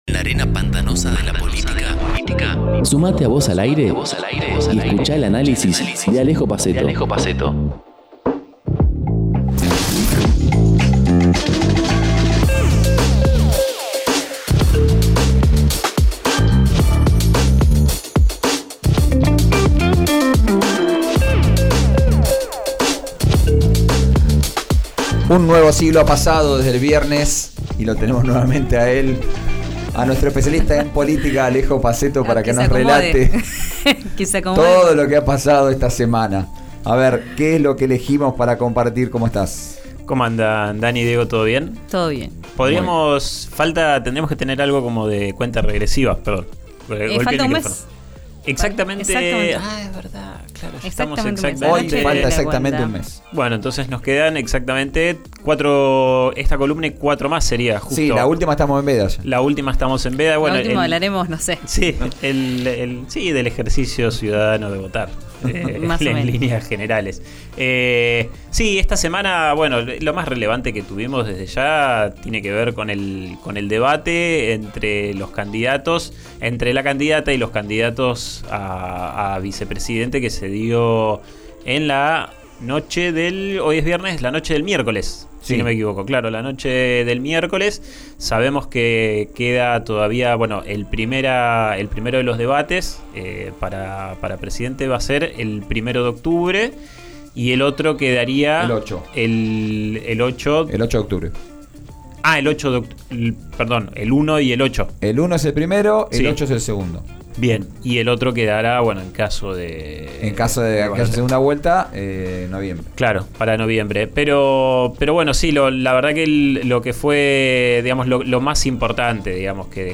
Nueva columna en RÍO NEGRO RADIO